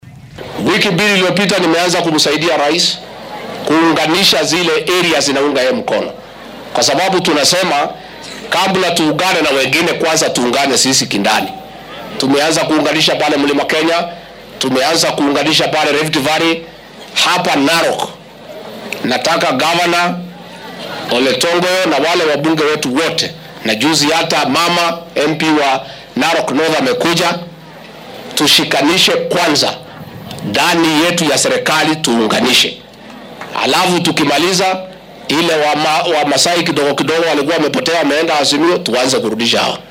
Madaxweyne ku xigeenka dalka Rigathi Gachagua ayaa siyaasiyiinta ka tirsan isbeheysiga talada wadanka haya ee Kenya Kwanza ku boorriyay inay muujiyaan midnimo buuxda. Waxaa uu sheegay inuu dadaal ku bixiyay mideynta hoggaamiyaasha gobolka bartamaha dalka ka hor inta uunan u gudbin gobollada kale. Xilli uu ku sugnaa ismaamulka Narok ayuu hoggaamiyaha labaad ee dalka sheegay in madaxda ismaamullada iyo mas’uuliyiinta dowladda sare laga doonayo inay ka wada shaqeeyaan wanaajinta nolosha shacabka iyo sare u qaadidda adeegyada bulshada.